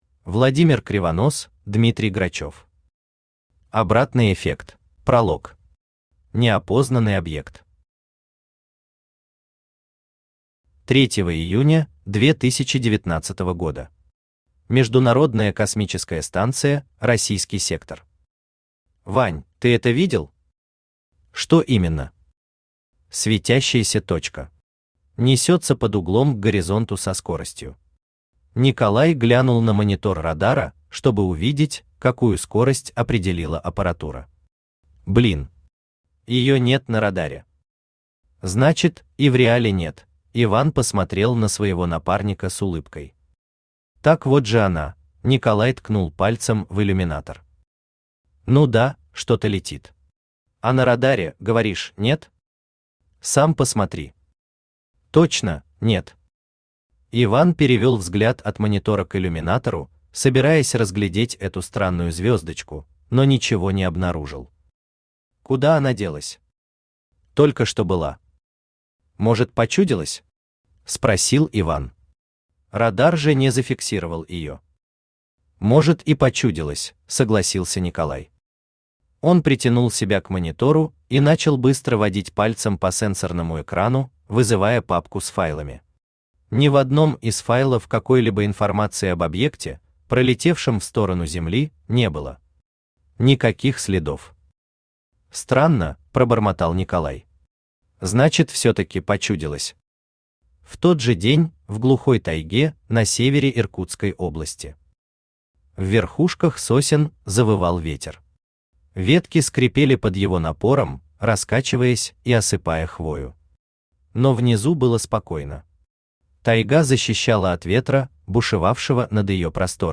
Аудиокнига Обратный эффект | Библиотека аудиокниг
Aудиокнига Обратный эффект Автор Владимир Андреевич Кривонос Читает аудиокнигу Авточтец ЛитРес.